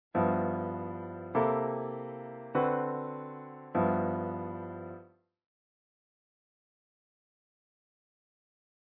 The blues.
When we combine this with 13, we gain a solid functioning blues tonic structure chord that supports a number of different approaches of blue melodies, i.e., blues rock, country blues, jazz etc. This next bluesy V 13 voicing is very common. Here we use it as a constant structure to cover the principle One / Four / Five / One chords of our blues.